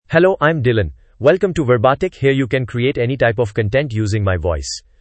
Dylan — Male English (India) AI Voice | TTS, Voice Cloning & Video | Verbatik AI
MaleEnglish (India)
Dylan is a male AI voice for English (India).
Voice sample
Dylan delivers clear pronunciation with authentic India English intonation, making your content sound professionally produced.